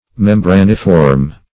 Search Result for " membraniform" : The Collaborative International Dictionary of English v.0.48: Membraniform \Mem*bra"ni*form\, a. [Membrane + -form: cf. F. membraniforme.] Having the form of a membrane or of parchment.